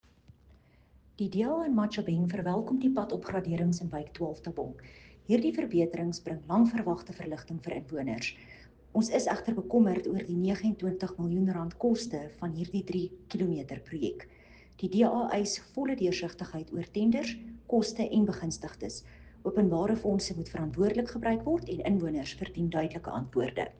Afrikaans soundbite by Cllr René Steyn and